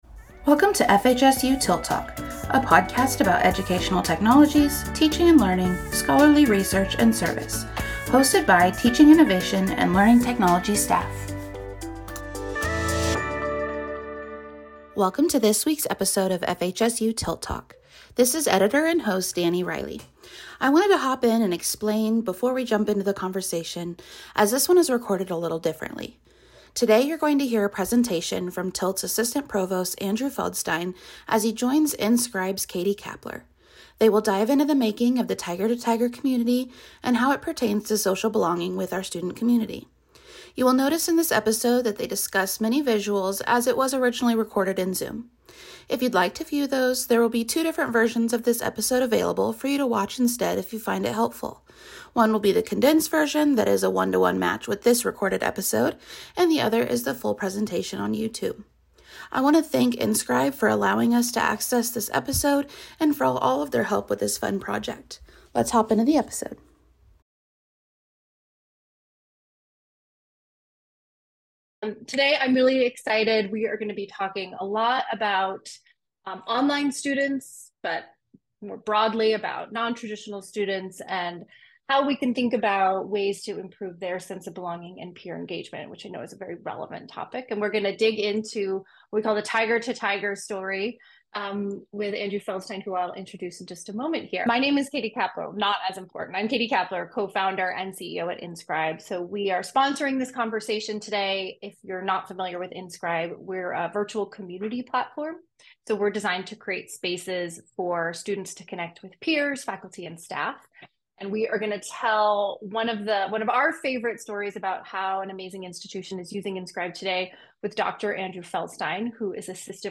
This webinar was previously recorded and posted to YouTube by Inscribe.